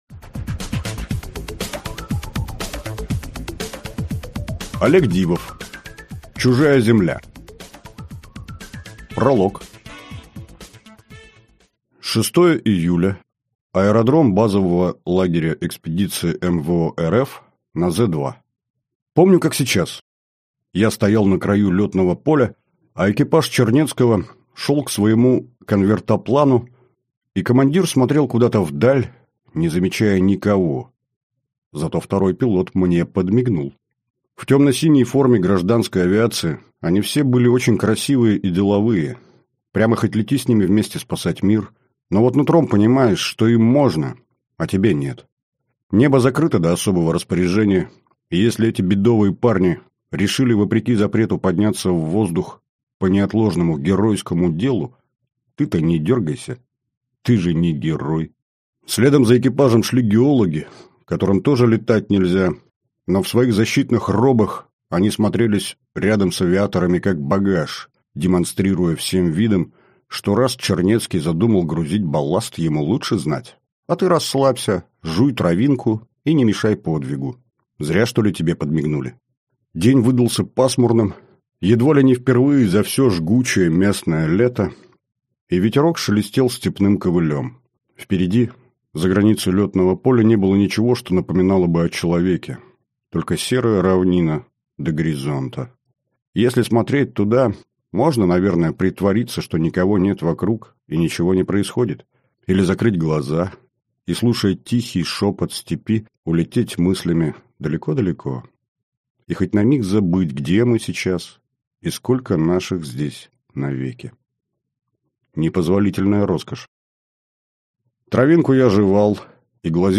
Аудиокнига Чужая Земля | Библиотека аудиокниг